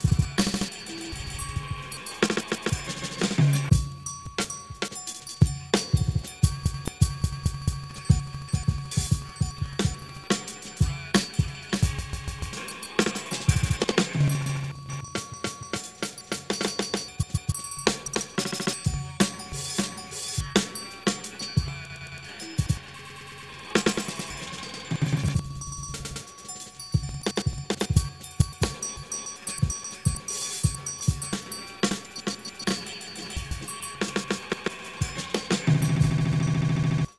Beat Repeater is a VST and AU stereo audio plugin that manipulates the wet, dry, and feedback gains of a simple delay line.
beatRepeatPlugin_excerpt.mp3